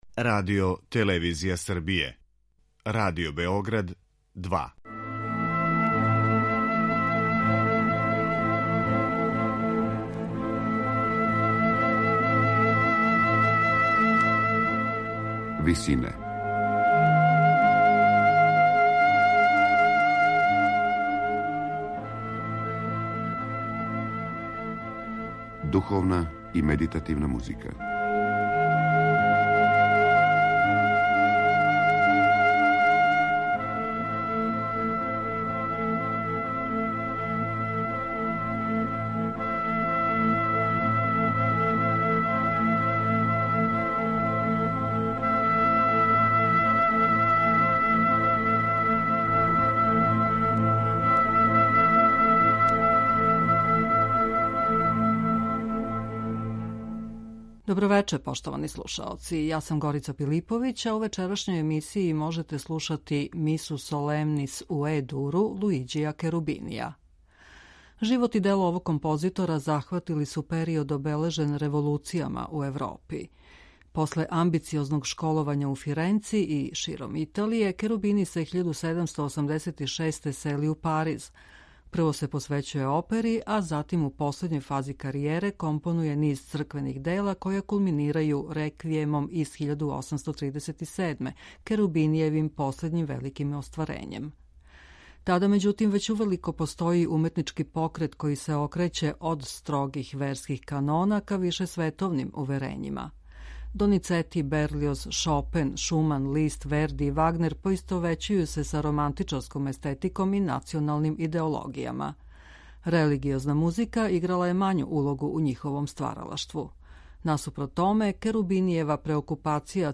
У вечерашњој емисији Висине, можете слушати Мису солемнис у Е-дуру Луиђија Керубинија.